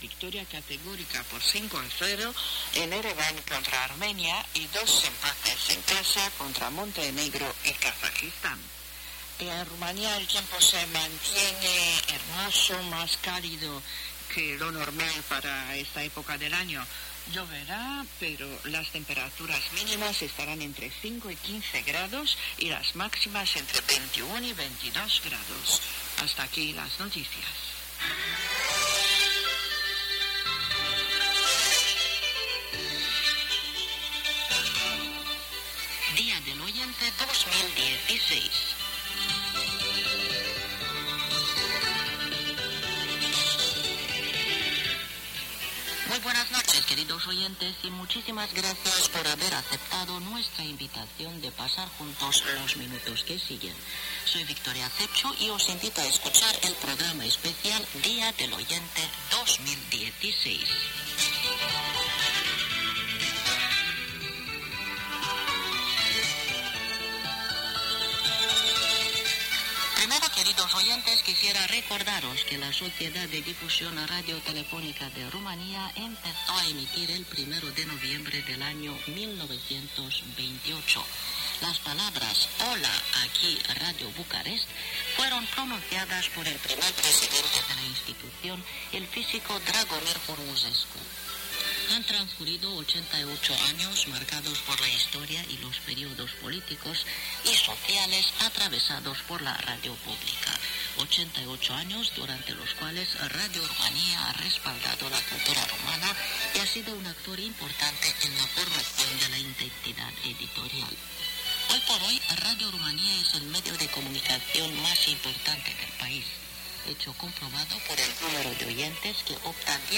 Escucha Radio Rumanía Internacional, programa especial El Día del Oyente, transmitido por onda corta.